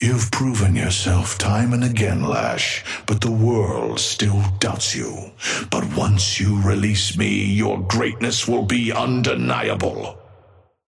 Amber Hand voice line - You've proven yourself time and again, Lash, but the world still doubts you. But once you release me, your greatness will be undeniable.
Patron_male_ally_lash_start_05.mp3